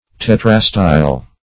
Search Result for " tetrastyle" : The Collaborative International Dictionary of English v.0.48: Tetrastyle \Tet"ra*style\, a. [L. tetrastylon, Gr.